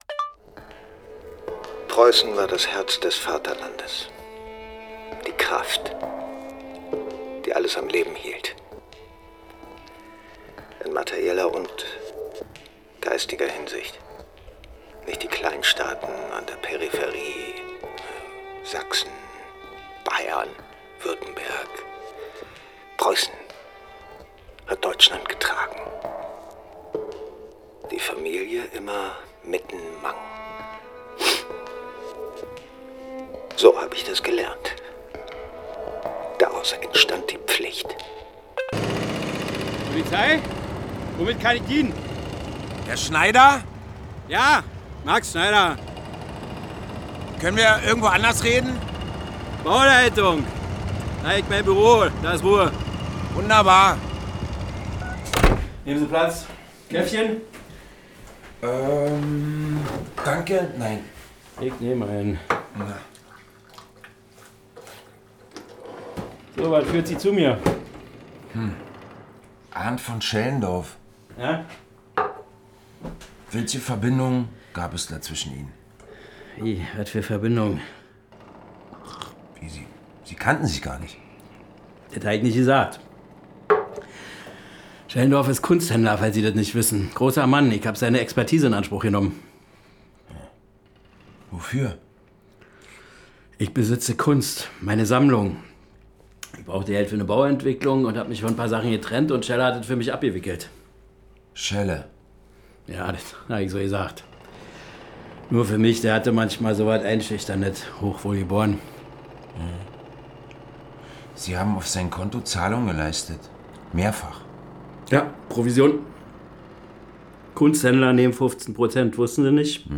Radio | Hörspiel
Radio-Tatort